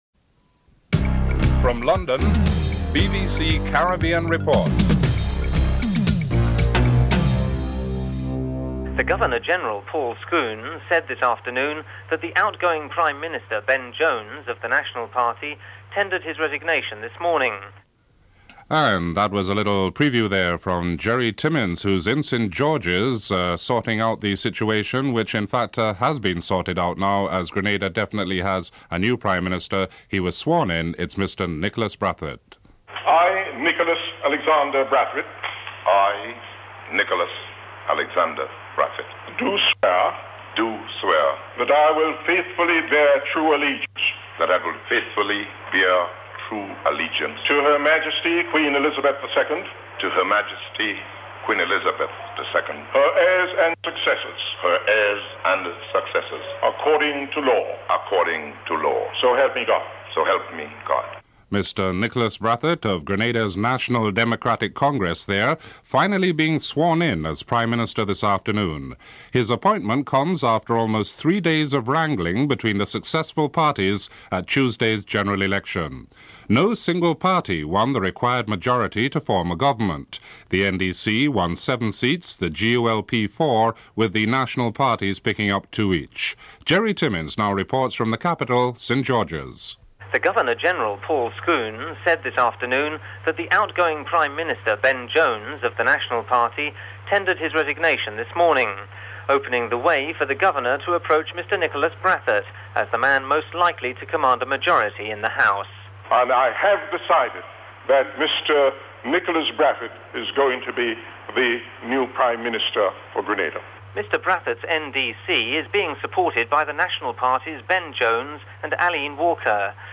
Guyana Support Group holds an emergency meeting in Washington.